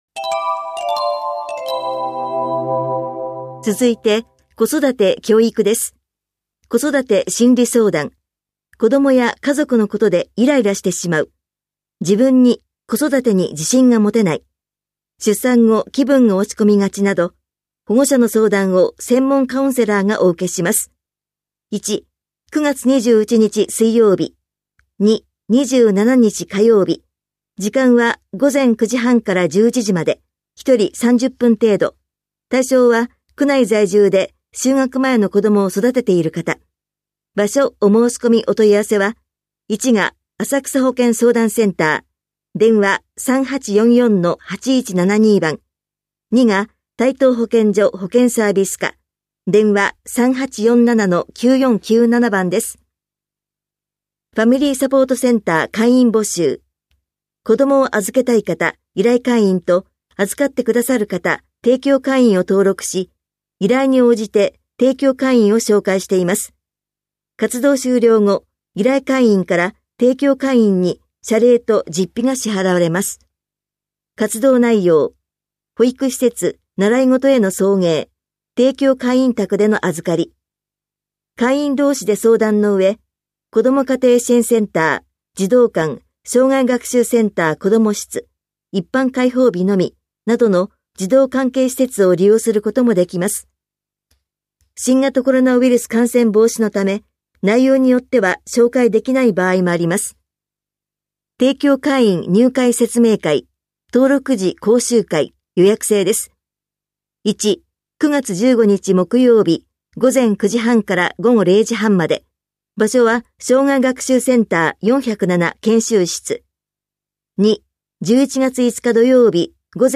広報「たいとう」令和4年8月20日号の音声読み上げデータです。